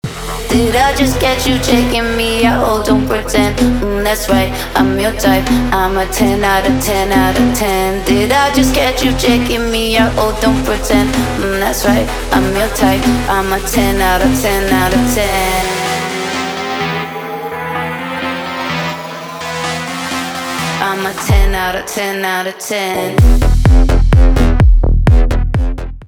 танцевальные
битовые , басы , качающие , кайфовые , нарастающие